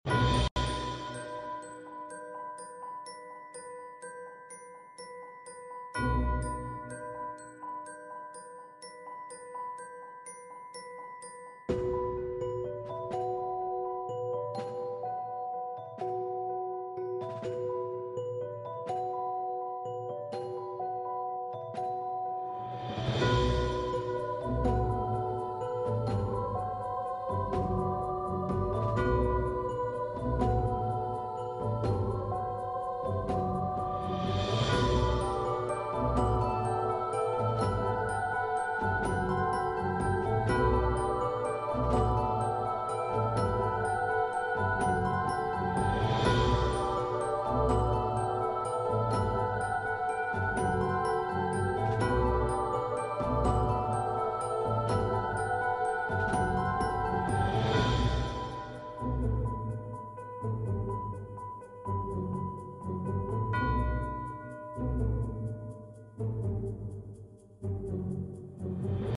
christmas event level music